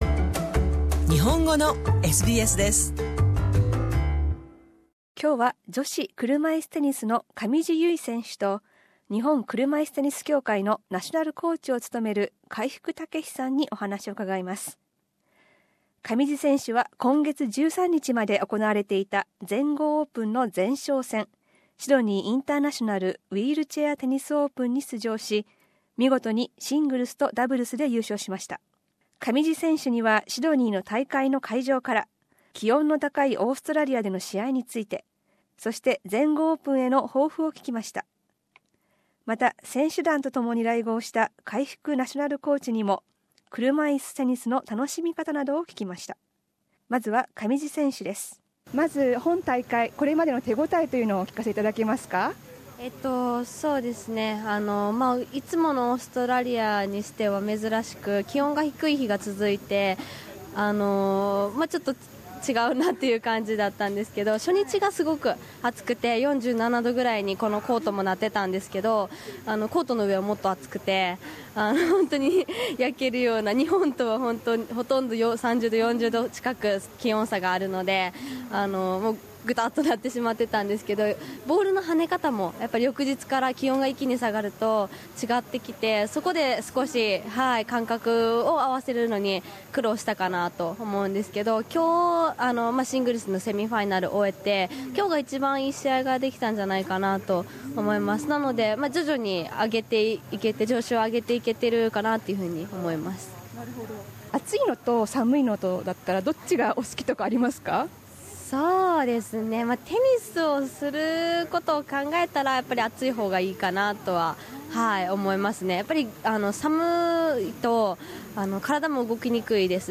上地選手にはこのシドニーの試合会場で、オーストラリアでの試合について、そして全豪オープンへの抱負などを聞きました。